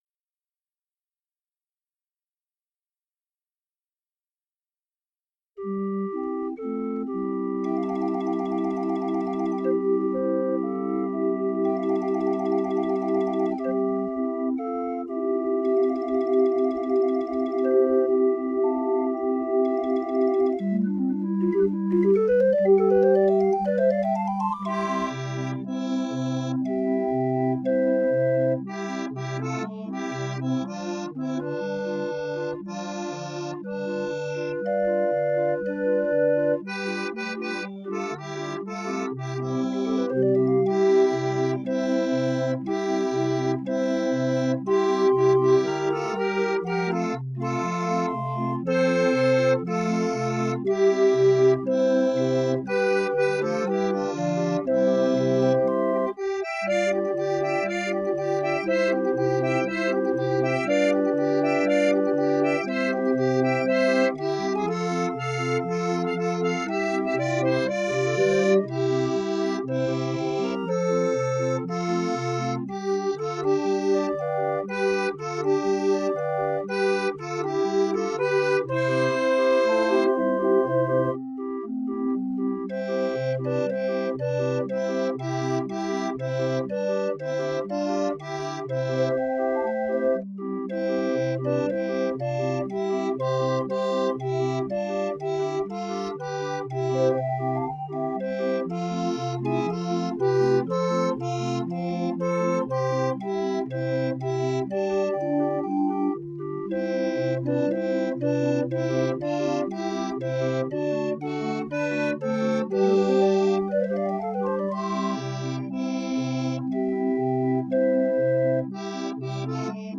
l’orgue musette
l’orgue � fl�tes
5 orgues